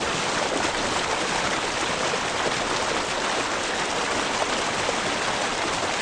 fast_water.wav